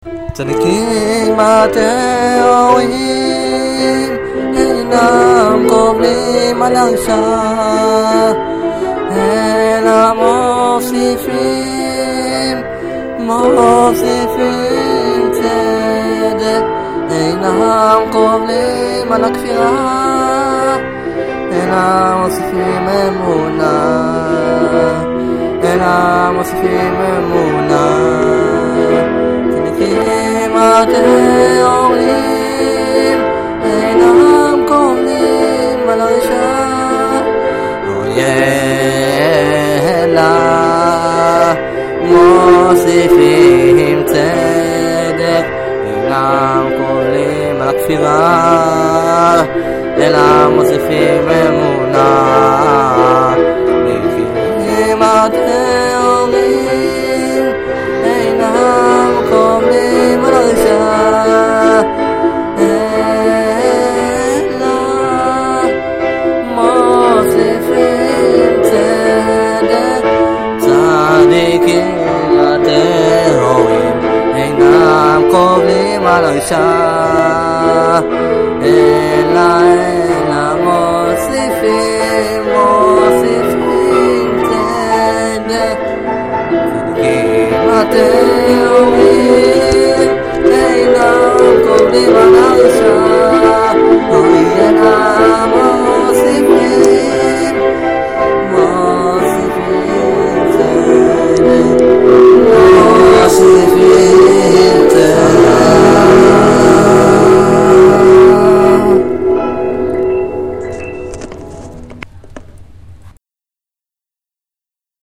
שירה: האף שלי...